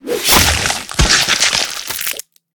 slash.ogg